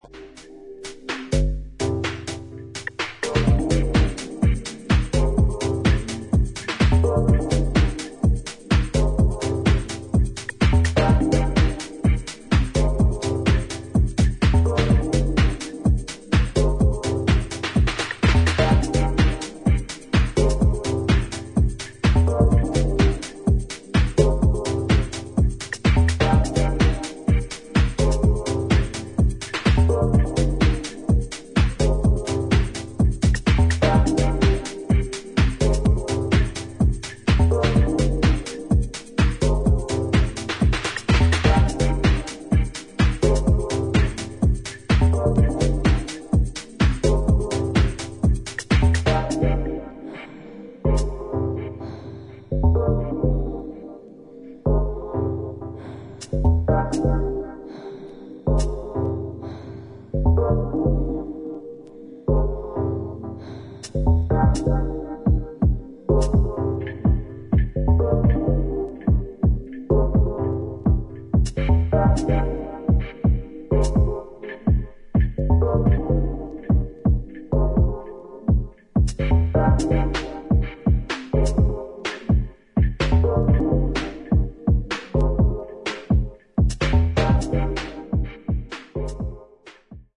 新旧どのテクノ・ハウスサウンドにも対応するであろう、円熟味を帯びた一枚です。